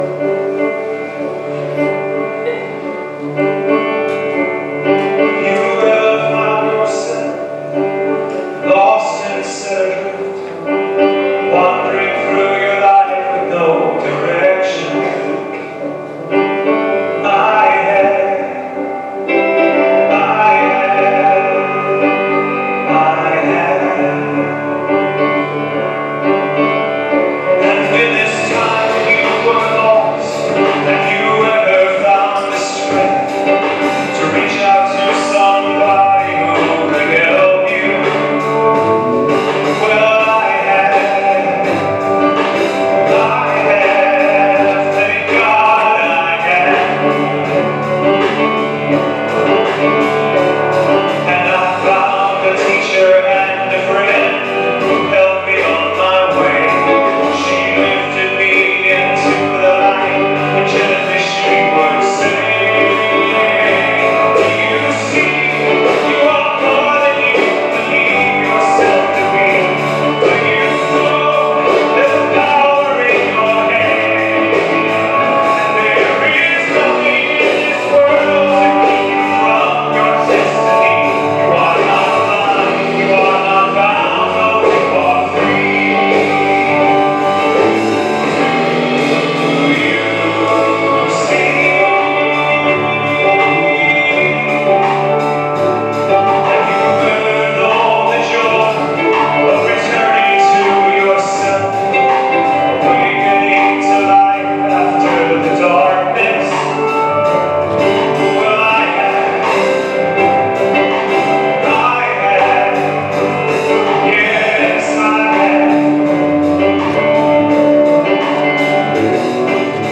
Spiritual Leader Series: Sermons 2024 Date